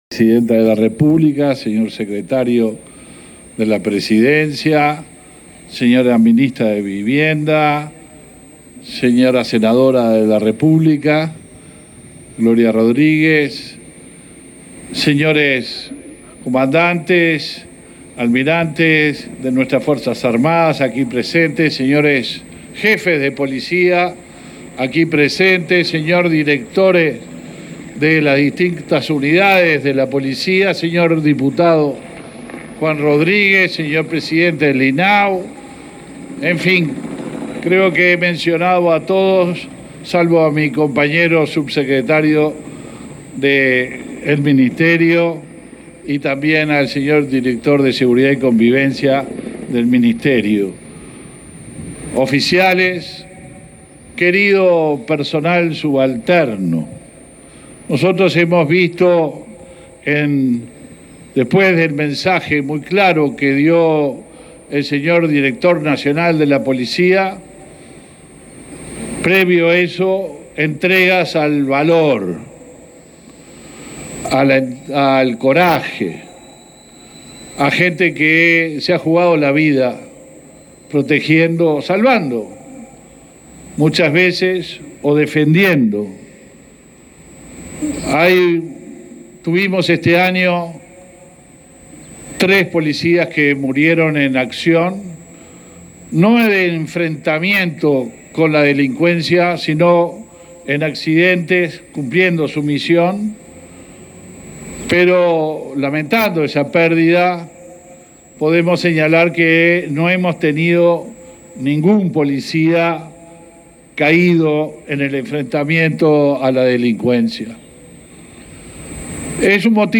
Palabras del ministro del Interior, Luis Alberto Heber
Palabras del ministro del Interior, Luis Alberto Heber 19/12/2022 Compartir Facebook X Copiar enlace WhatsApp LinkedIn Este19 de diciembre se realizó el acto de conmemoración del 193.° aniversario de la creación de la Policía Nacional, con la presencia del ministro del Interior, Luis Alberto Heber.